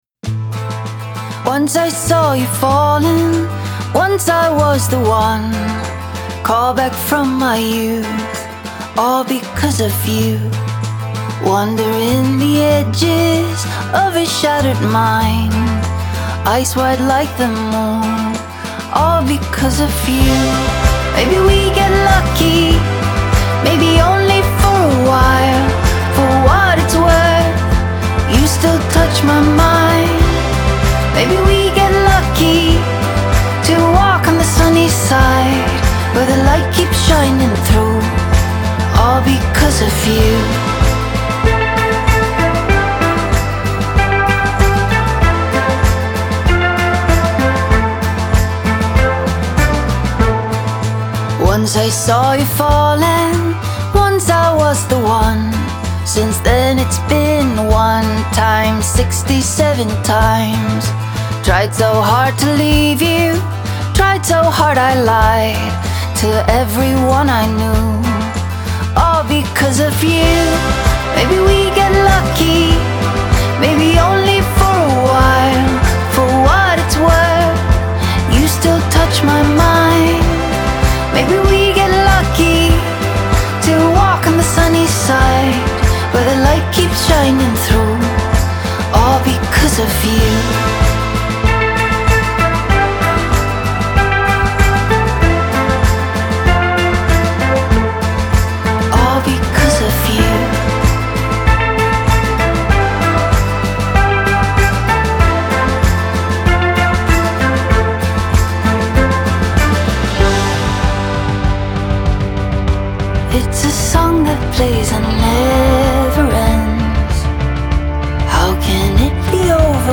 Genre : Singer & Songwriter